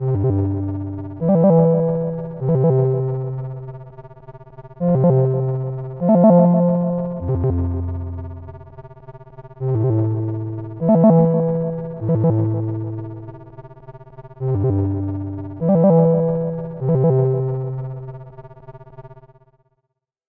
Стандартный рингтон